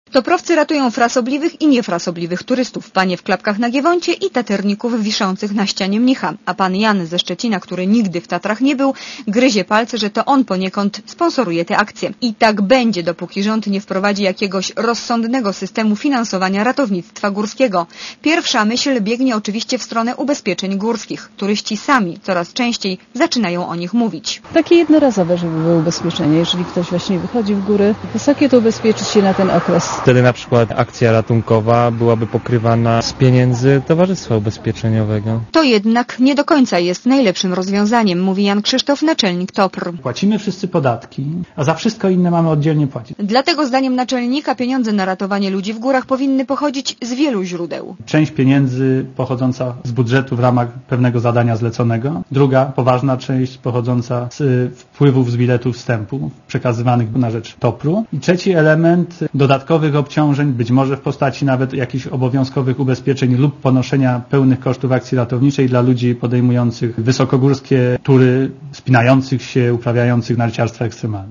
Posłuchaj relacji reporterki Radia Zet z Zakopanego(277 KB)